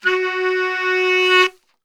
F#2 SAXSWL.wav